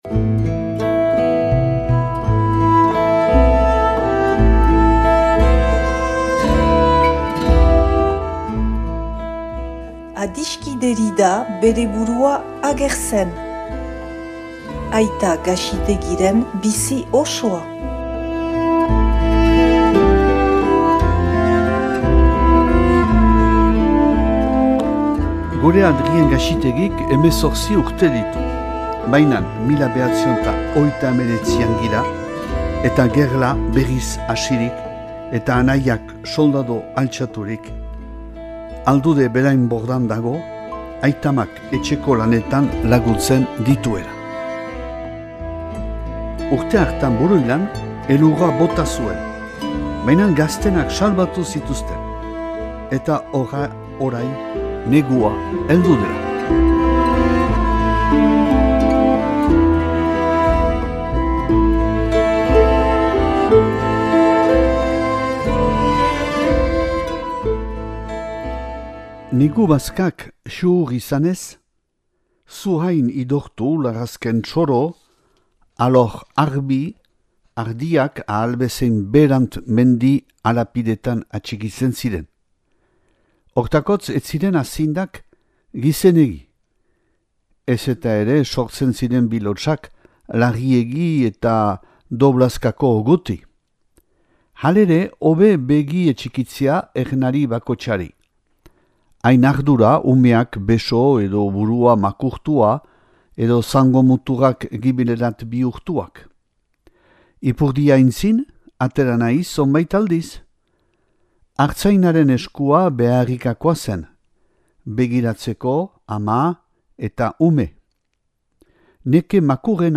irakurketa bat dauzuegu eskaintzen.